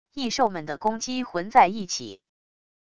异兽们的攻击混在一起wav音频